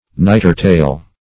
Search Result for " nightertale" : The Collaborative International Dictionary of English v.0.48: Nightertale \Night"er*tale\, n. [Cf. Icel. n[=a]ttarpel.] Period of night; nighttime.
nightertale.mp3